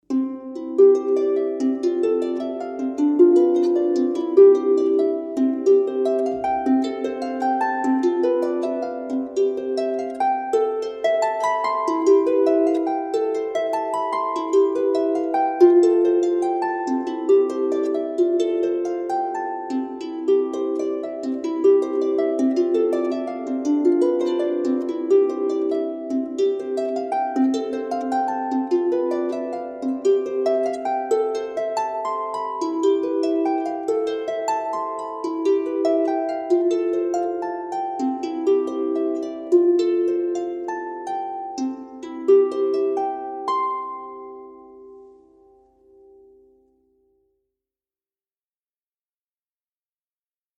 Acompanhamento Musical
Instrumental Harpa
12-instrumental_harpa.mp3